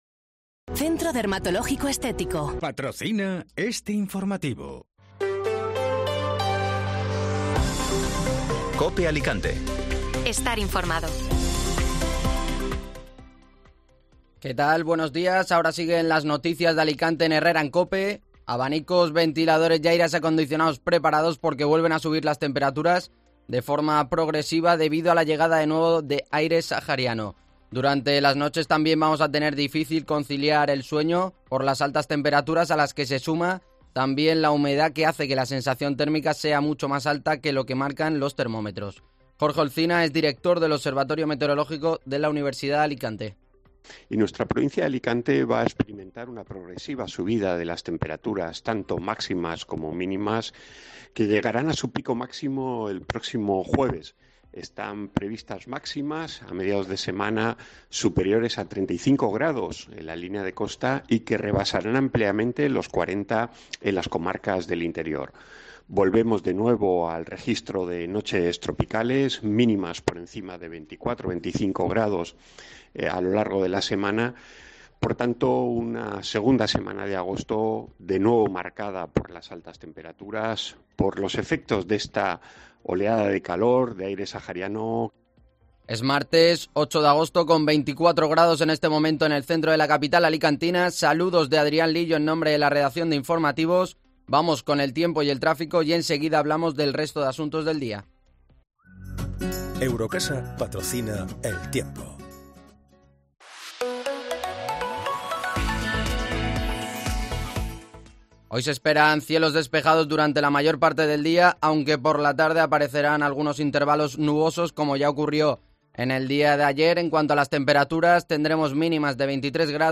Informativo Matinal (Martes 8 de Agosto)